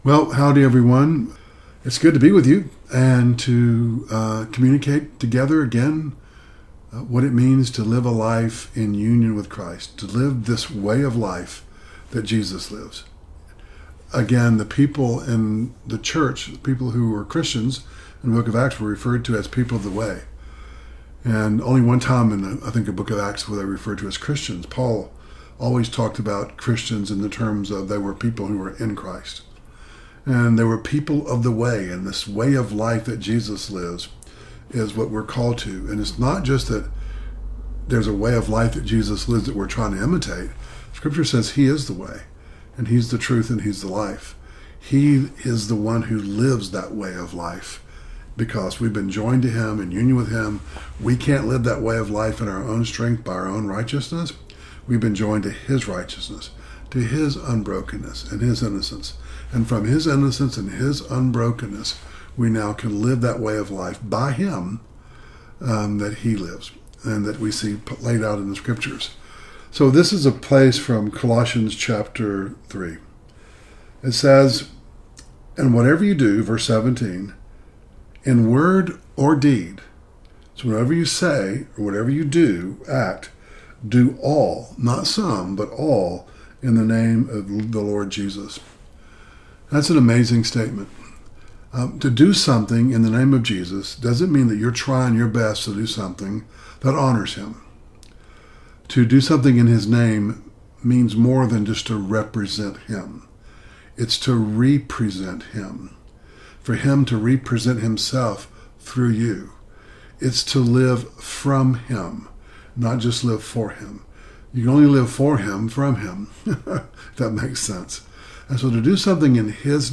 Originally on Facebook Live 2/26/25